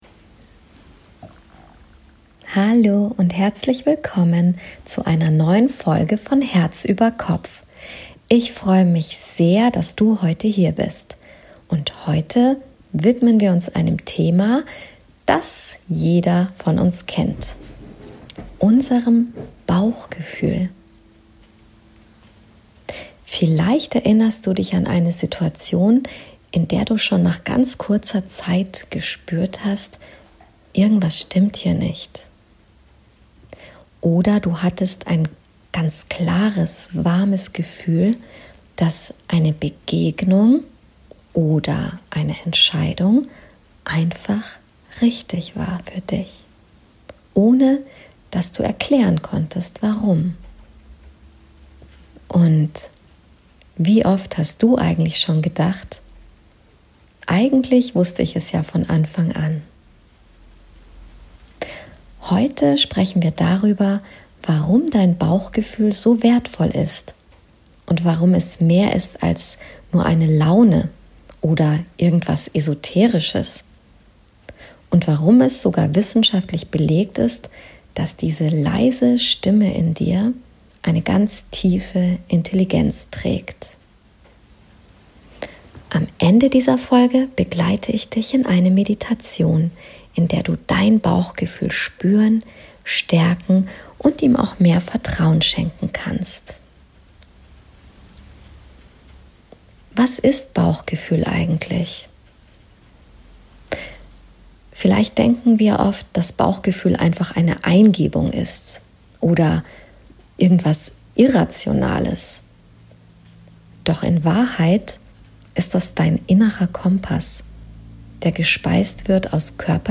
Ich teile mit dir spannende wissenschaftliche Hintergründe aus Neurowissenschaft und Herzforschung und zeige Dir, warum Intuition die klügste Form der Entscheidung sein kann. Am Ende dieser Folge begleite ich Dich in eine kraftvolle Meditation, die Dich mit Deinem Bauchgefühl verbindet und Dir hilft, Deinem inneren Kompass wieder mehr zu vertrauen.